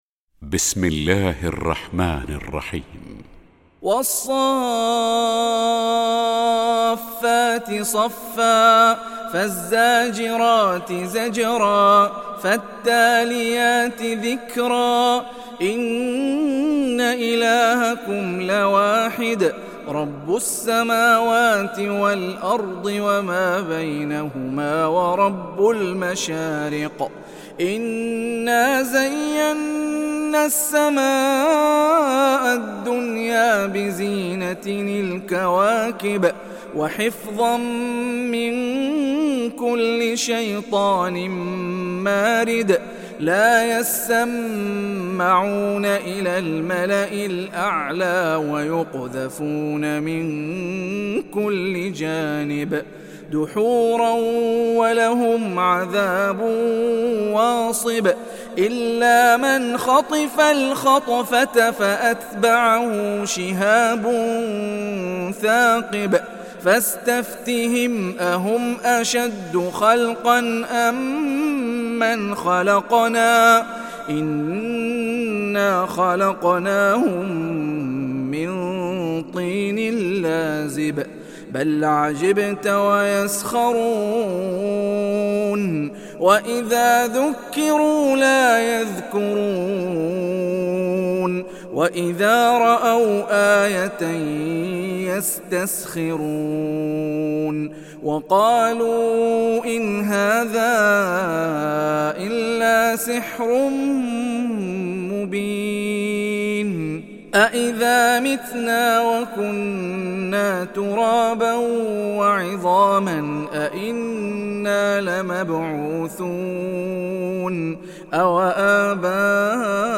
Saffet Suresi İndir mp3 Hani Rifai Riwayat Hafs an Asim, Kurani indirin ve mp3 tam doğrudan bağlantılar dinle